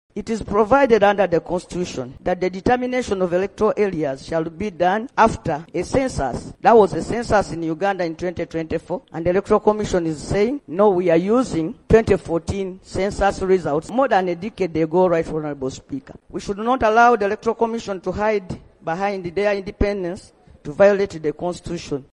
Hon. Nambooze (L) and Hon. Francis Zaake during the plenary.